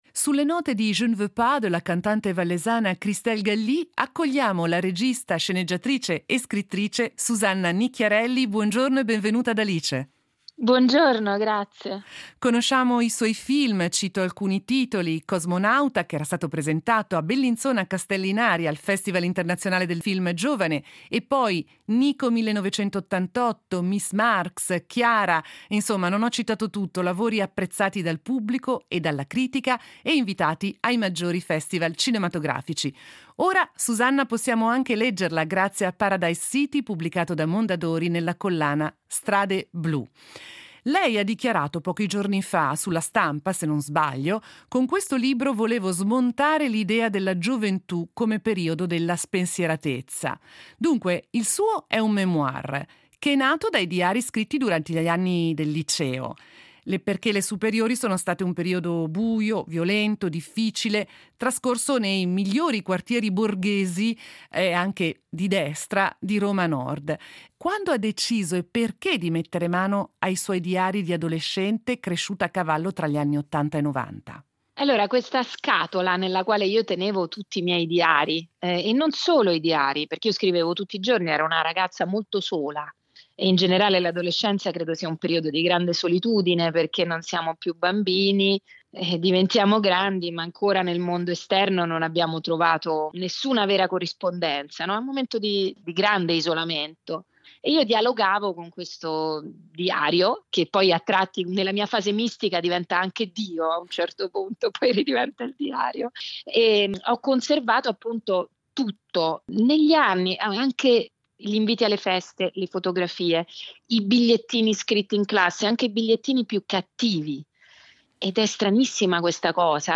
Intervista a Susanna Nicchiarelli